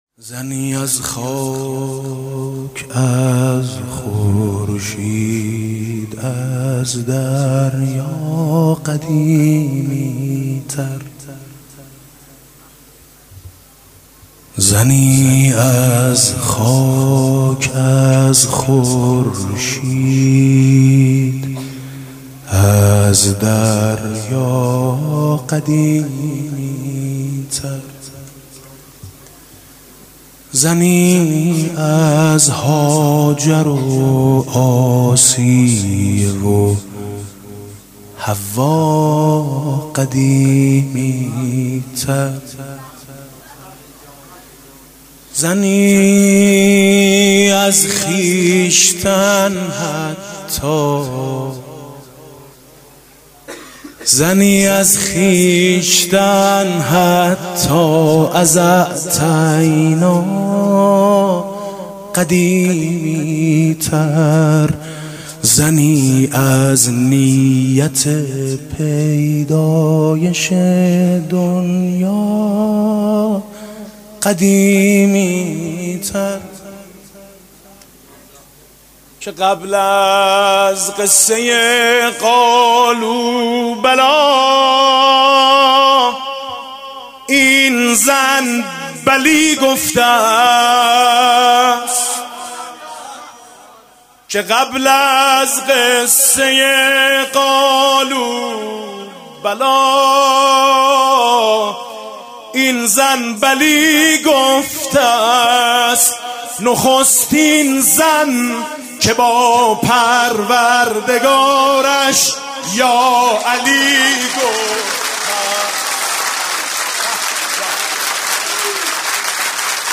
مدح: چه می فهمم من از زهرا و ما أدراک ما زهرا